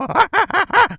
monkey_chatter_angry_04.wav